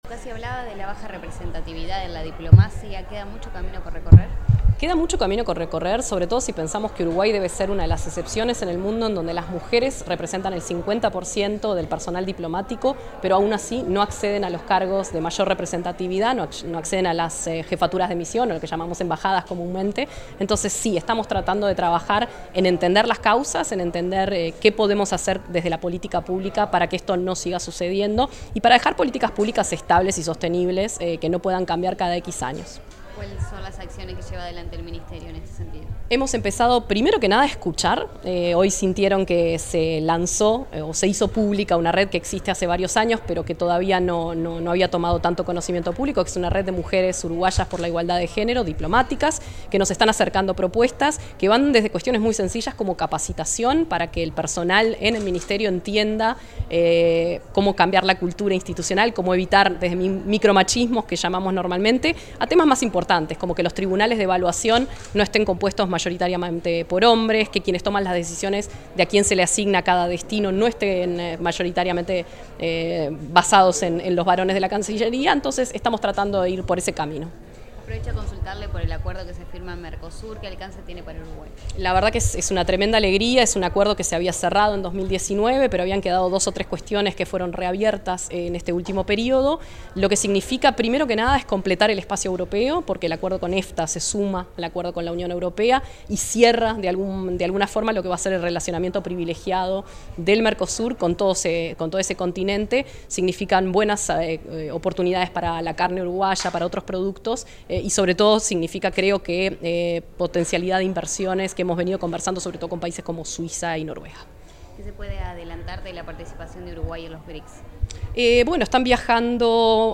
Declaraciones a la prensa de la canciller interina, Valeria Csukasi
Declaraciones a la prensa de la canciller interina, Valeria Csukasi 02/07/2025 Compartir Facebook X Copiar enlace WhatsApp LinkedIn Tras participar en la conmemoración del Día Internacional de la Mujer en la Diplomacia, la ministra interina de Relaciones Exteriores, Valeria Csukasi, dialogó con la prensa.